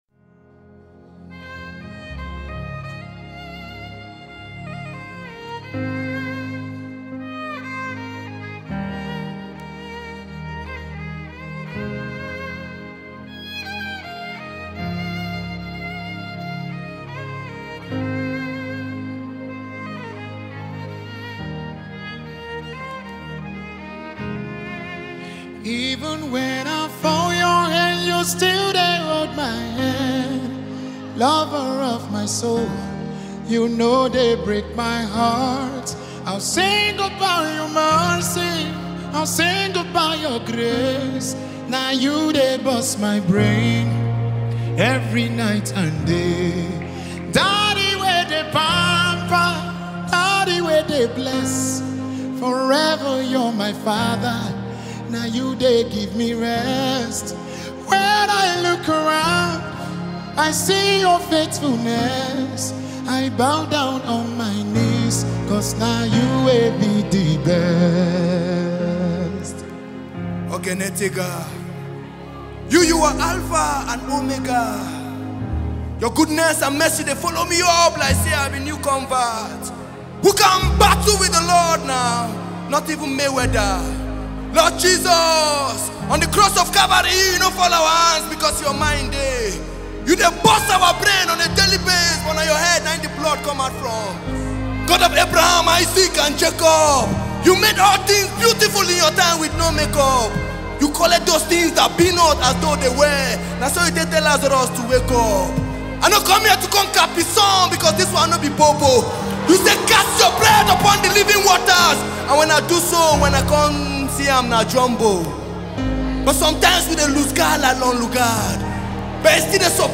soul-lifting gospel song
soul-stirring gospel anthem
Genre: Gospel